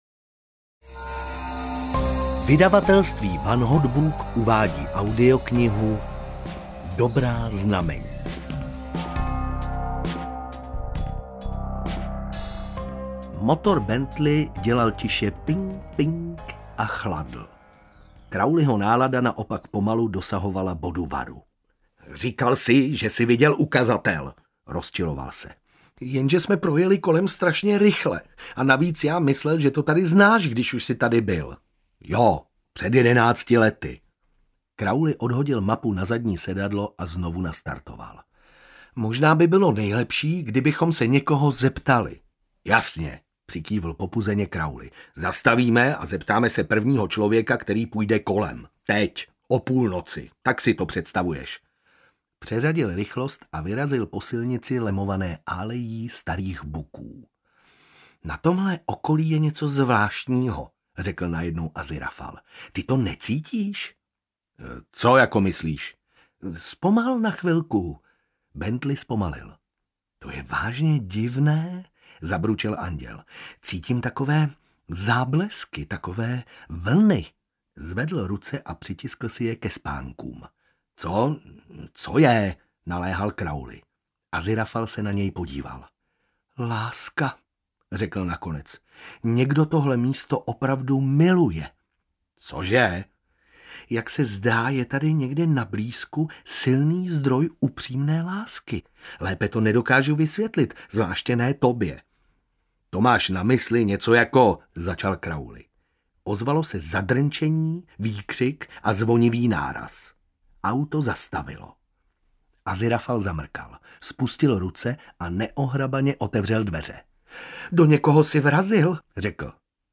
Dobrá znamení audiokniha
Ukázka z knihy